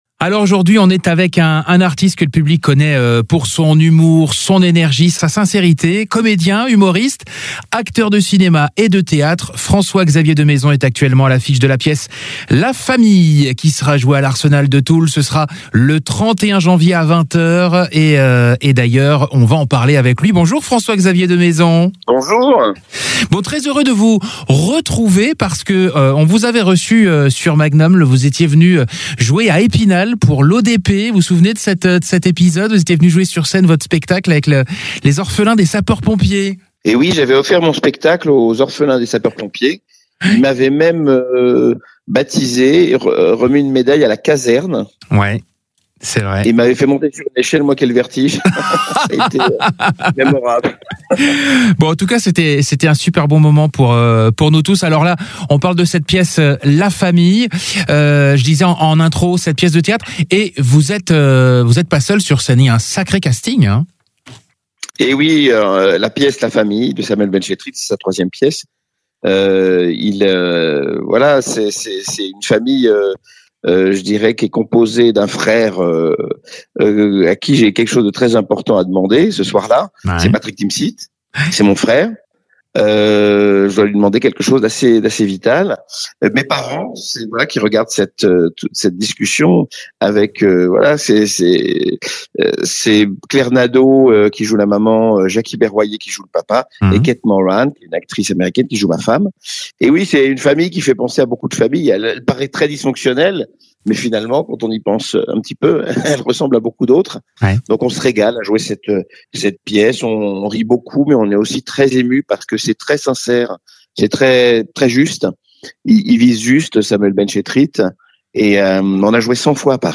INTERVIEW INTEGRALE